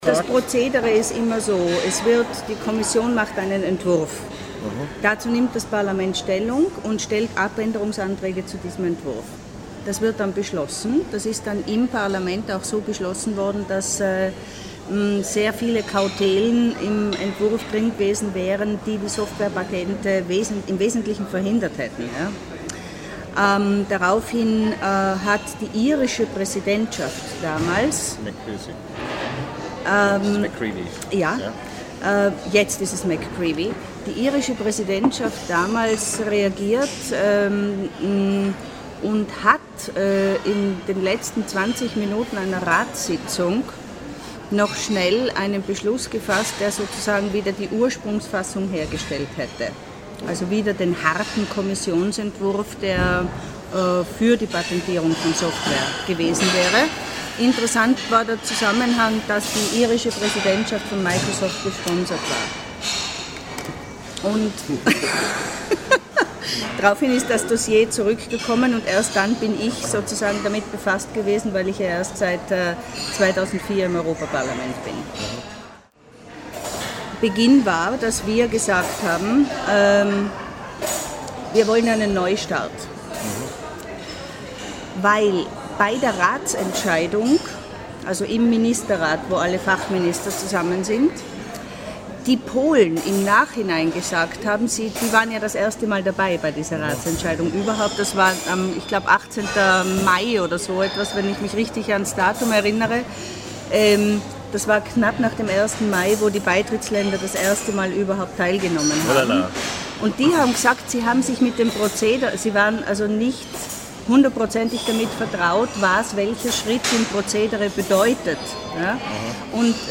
As a member of the European Parlament for the Austrian Green Party Eva Lichtenberger gives an introduction into the decision-making processes of the European Parliament and the political situation and state of discussion in Brussels when the debate about software patents reached new peaks in September 2004 and July 2005.